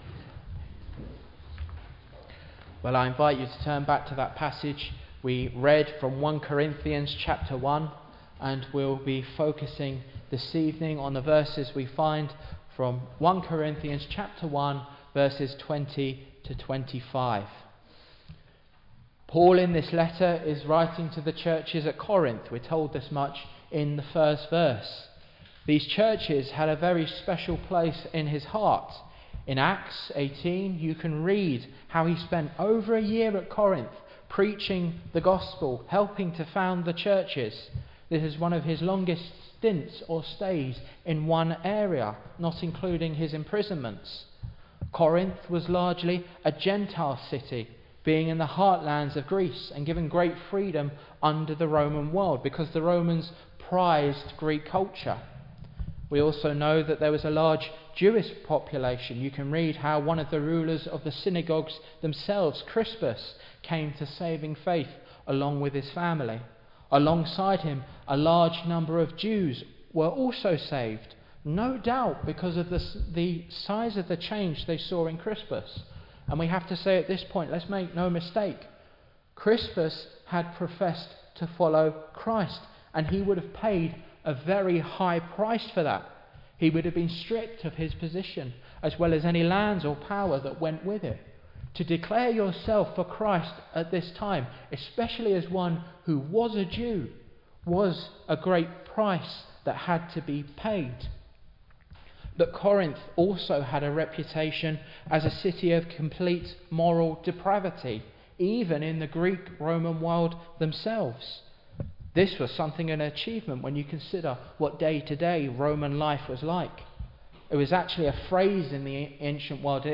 Teaching and Gospel sermons from 1 Corinthians